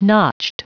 Prononciation du mot notched en anglais (fichier audio)
Prononciation du mot : notched
notched.wav